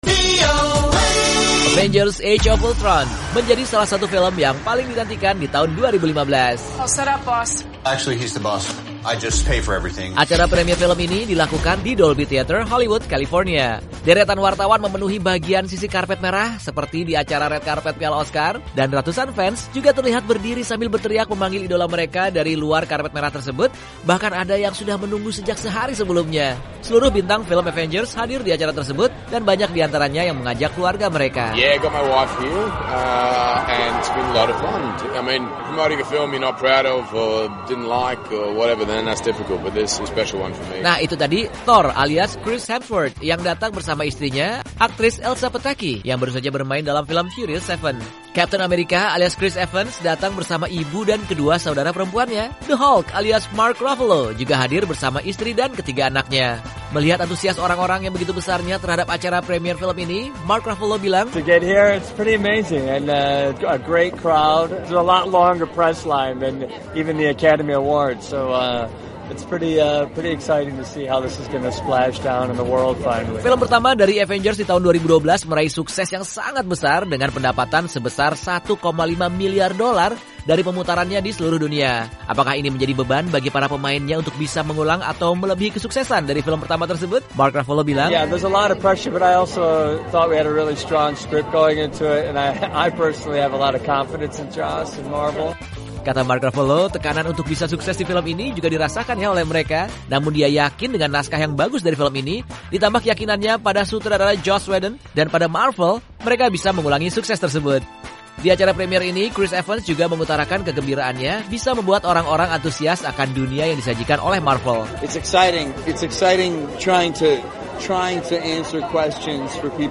Tidak hanya para fans, tetapi para bintang yang ikut bermain dalam film Avengers: Age of Ultron juga tidak sabar untuk menonton aksi mereka di layar lebar. Simak komentar mereka di acara pemutaran perdana film Avengers: Age of ultron baru-baru ini.